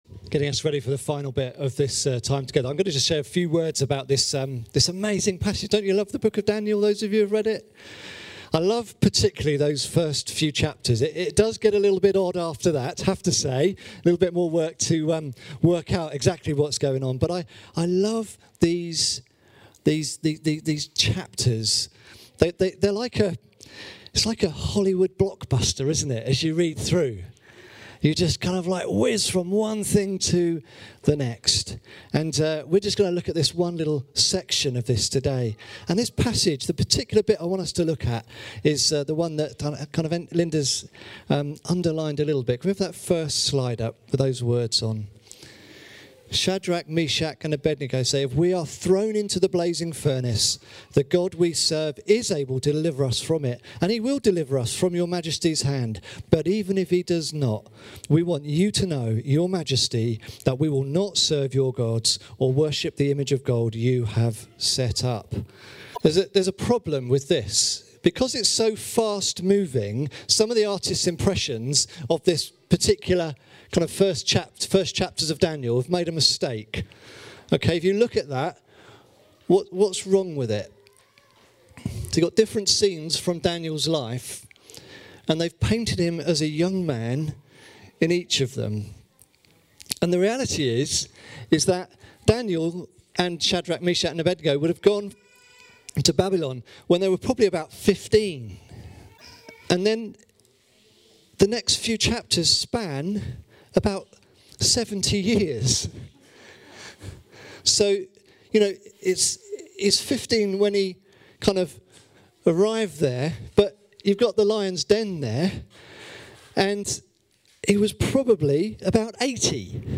24 March 2019 sermon (12 minutes)